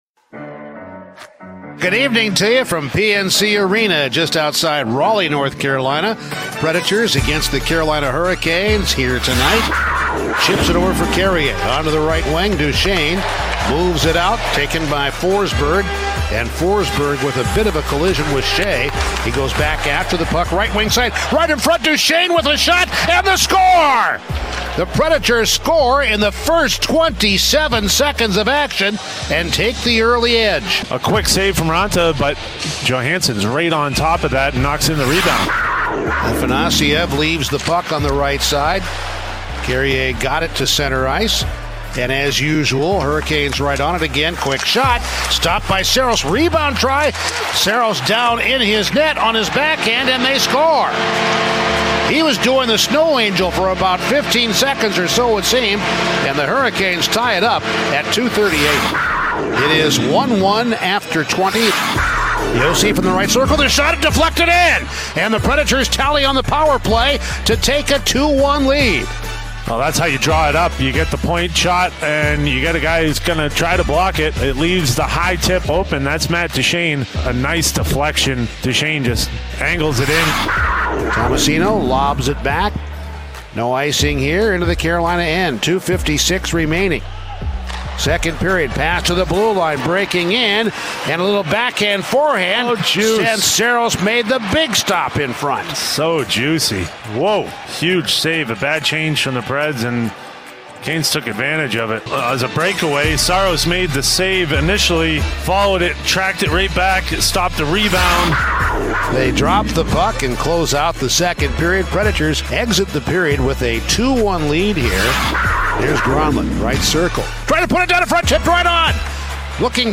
Full radio highlights from the Preds' 3-2 preseason
on the call on-site in Raleigh